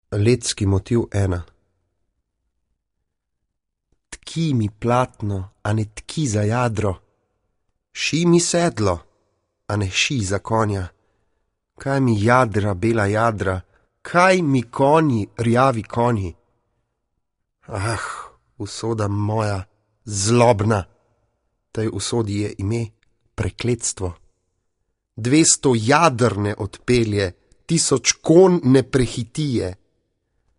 Recitacija   LETSKI MOTIV I